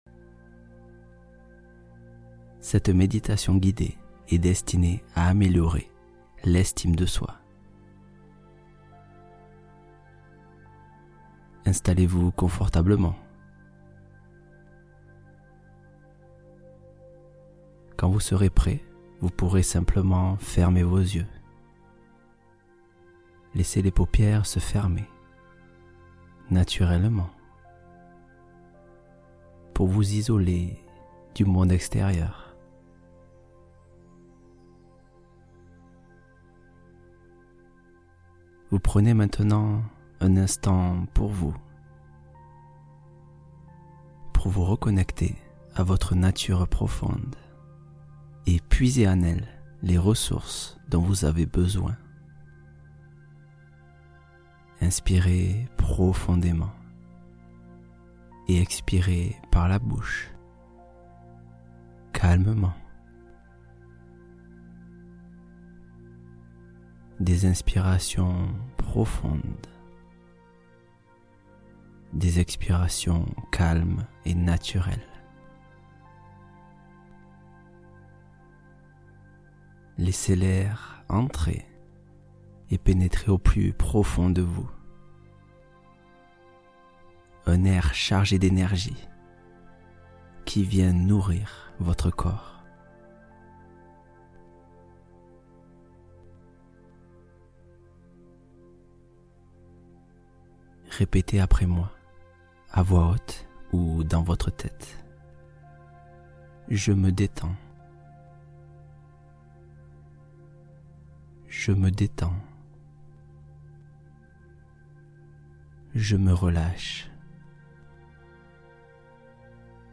Retrouvez-vous et Reconstruisez Votre Estime de Soi (Méditation Puissante)